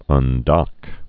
(ŭn-dŏk)